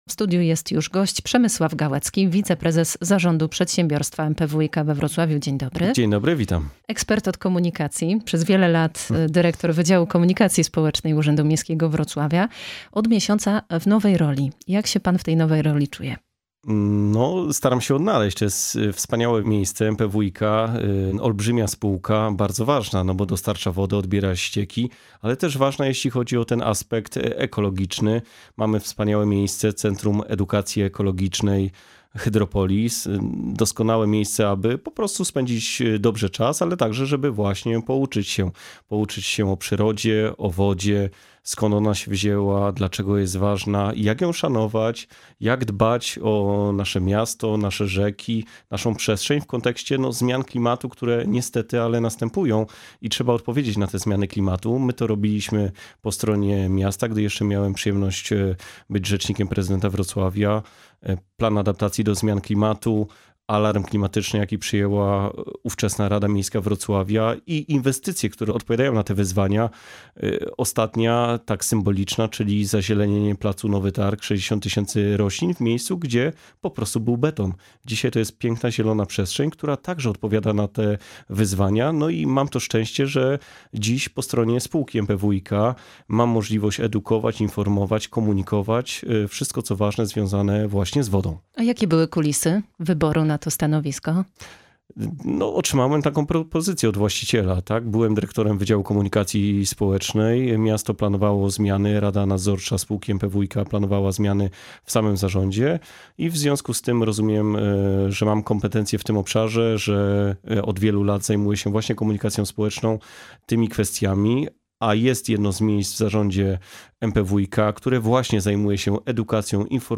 Pytamy w wywiadzie.